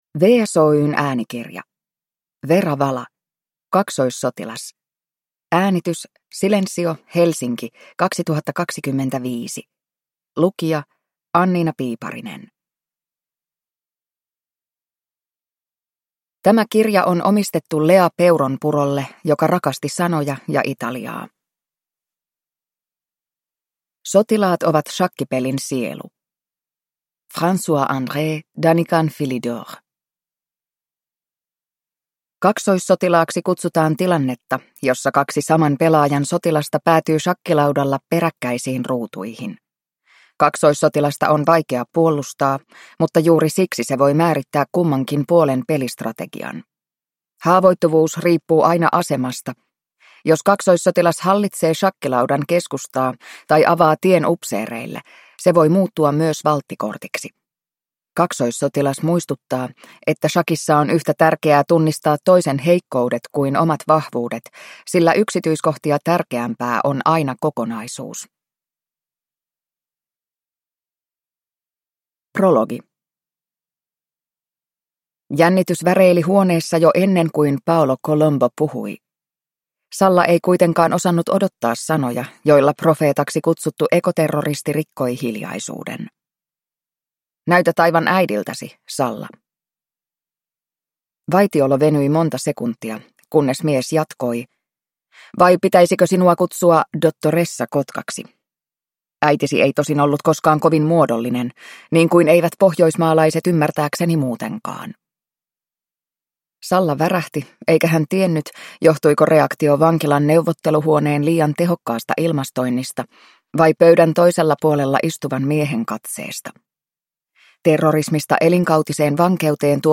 Kaksoissotilas (ljudbok) av Vera Vala